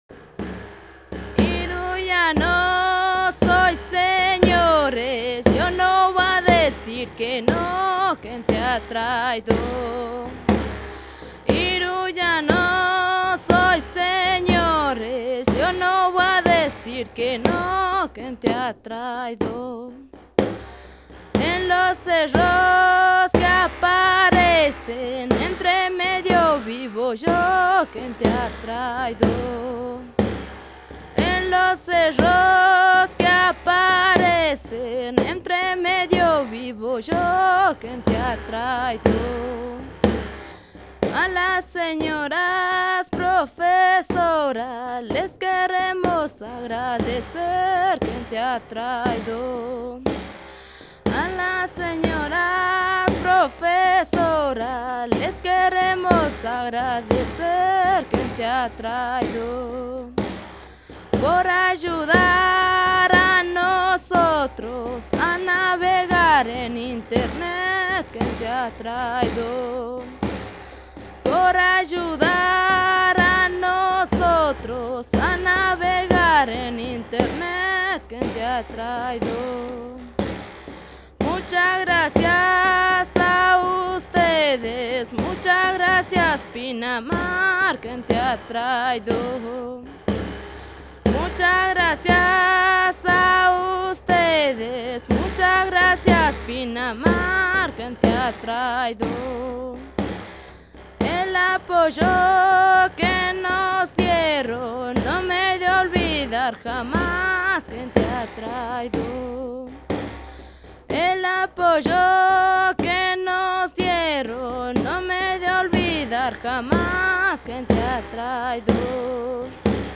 ARCHIVOS DE SONIDO "COPLAS" :